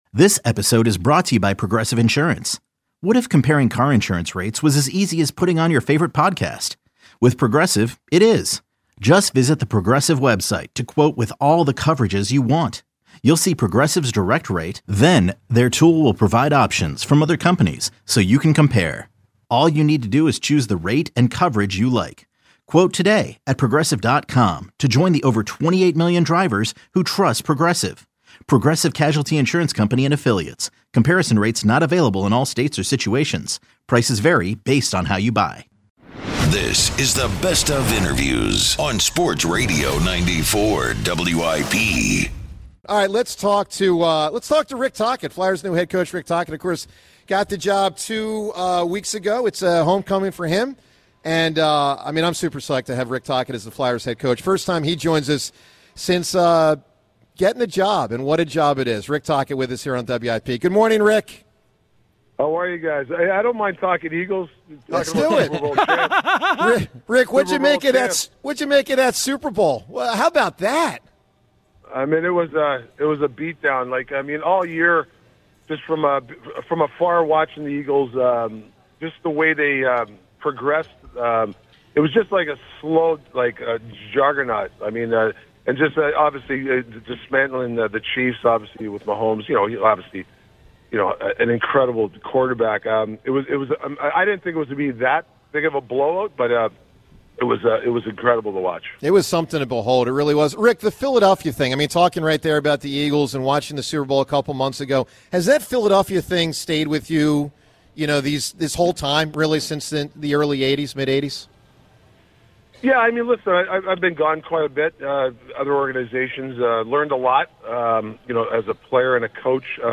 1 Best of Interviews on WIP: May 27-30 1:10:46